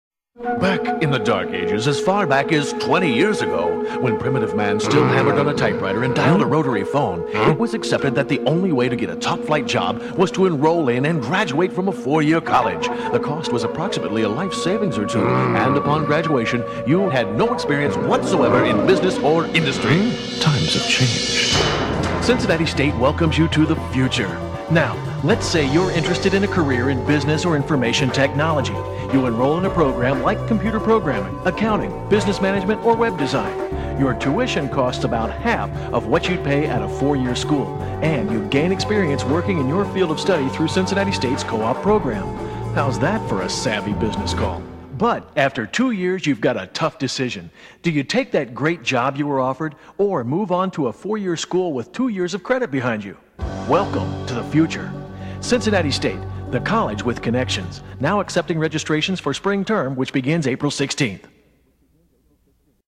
“The Dark Ages”, radio ad, 2001